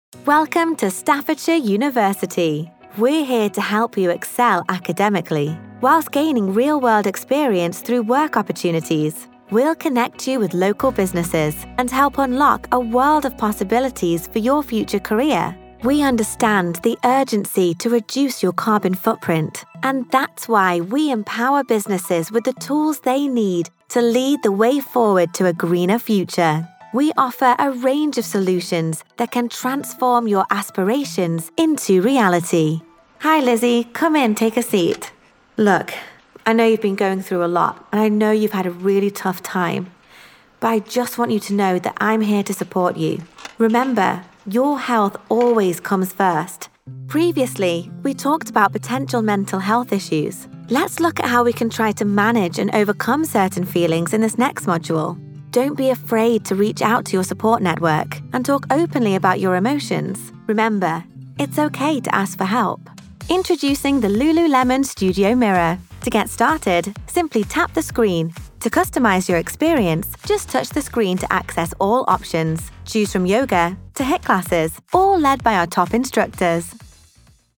Narration
Fun, fresh and vibrant.
She brings energy to any scripts that needs to ooze luxury with deep, warm, and silky tones, excite with heaps of enthusiasm, relate on a personal level, engage with technical information, and communicate serious topics with sincerity and understanding.